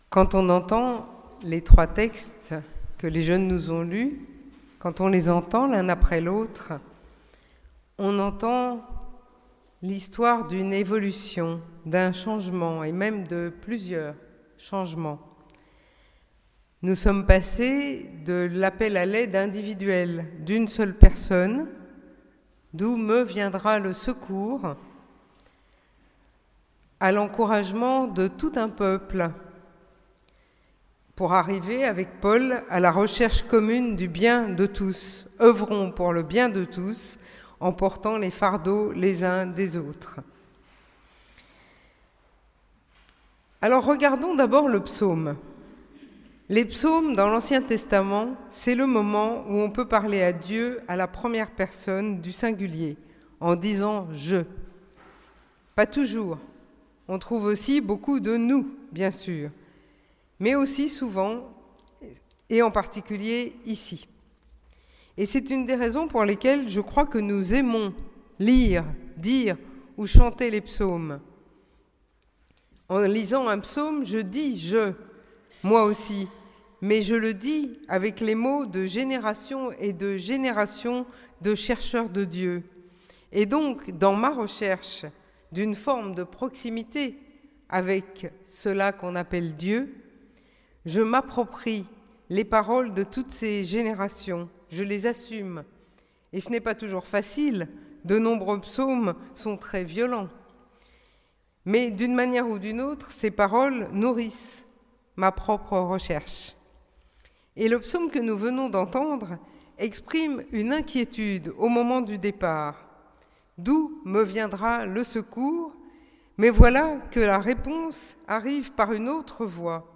Prédication du 30 septembre 2018
Culte de rentrée